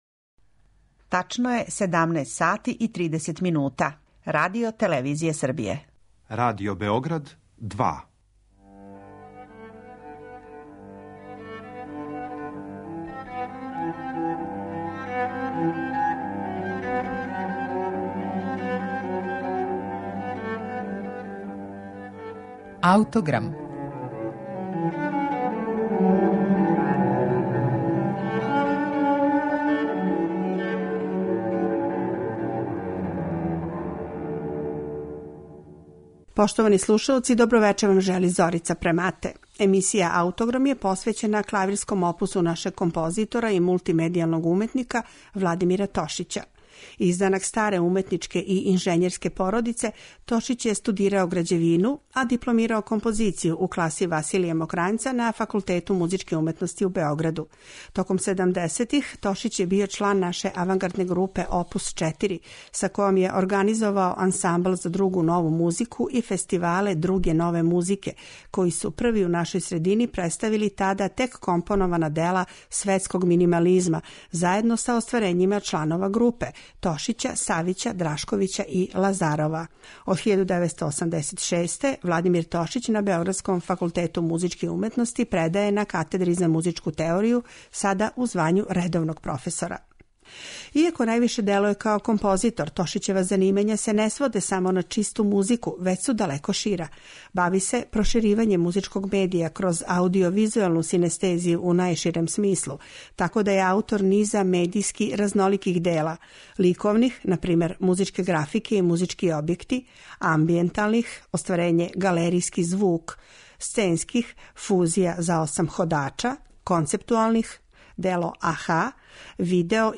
минималистичке стилске оријентације
пијаниста